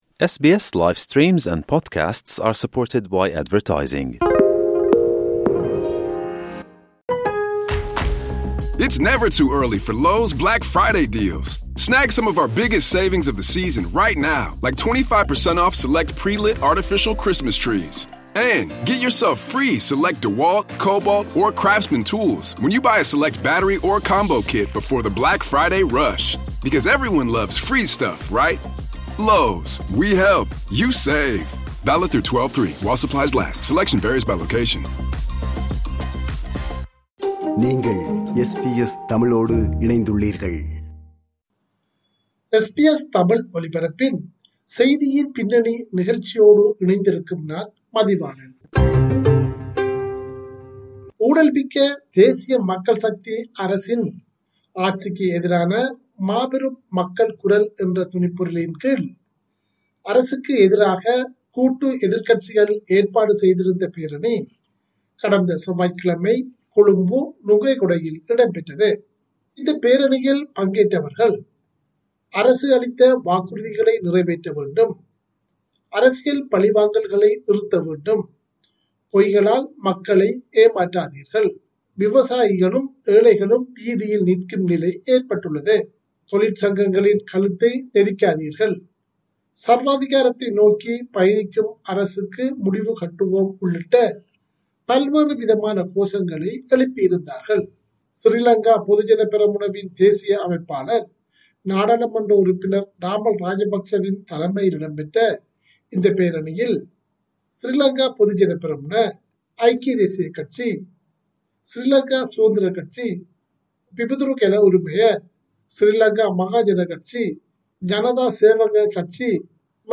"மாபெரும் மக்கள் குரல்" என்ற தொனிப்பொருளில் அரசுக்கு எதிரான பேரணி ஒன்று கூட்டு எதிர்க்கட்சியால் நடாத்தப்பட்டது. இது அரசை சீர்குலைக்கும் முயற்சி என ஆளுந்தரப்பு குற்றஞ்சாட்டியுள்ளது. இது தொடர்பில் பல்வேறு தரப்பினாினதும் கருத்துக்களோடு “செய்தியின் பின்னணி” நிகழ்ச்சி ஒன்றை முன்வைக்கிறார்